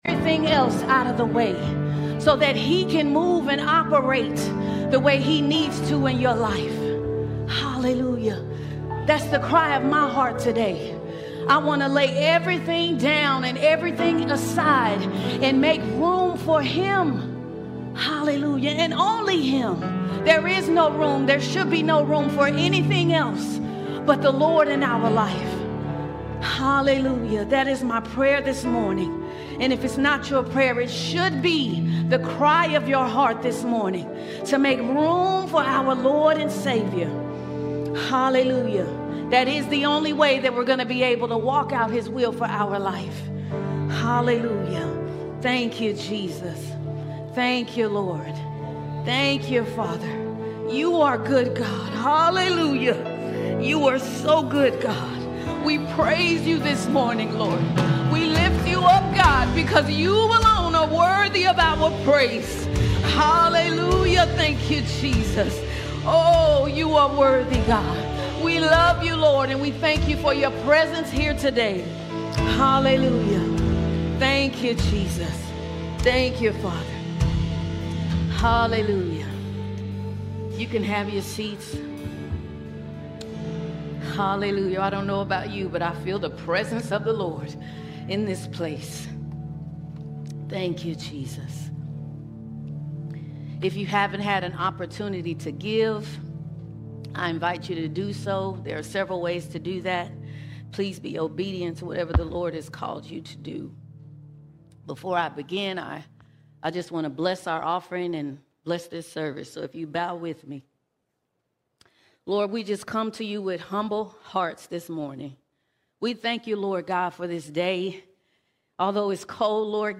14 December 2025 Series: Sunday Sermons All Sermons Separated Unto God Separated Unto God We’ve been called to live set apart, consecrated, holy, and devoted to God.